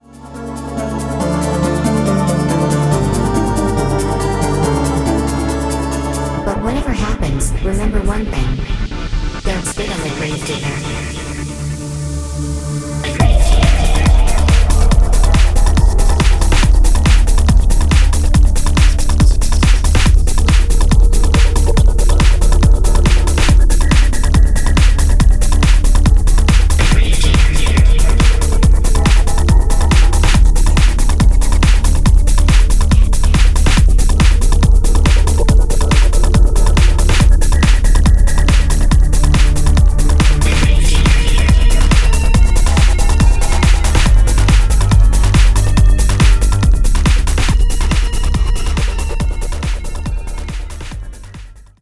• Genre : hard techno